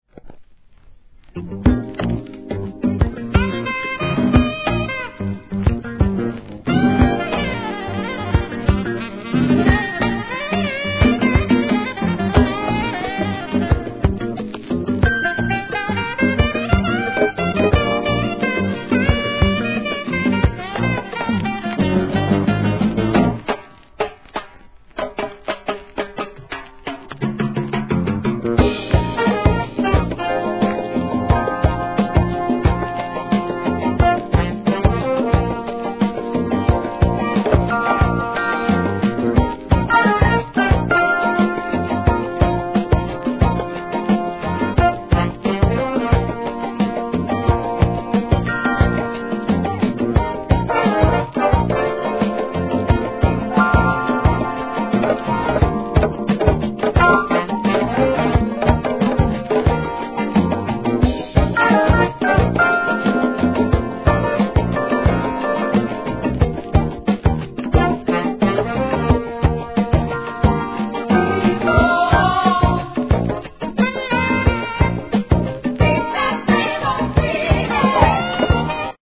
¥ 1,980 税込 関連カテゴリ SOUL/FUNK/etc...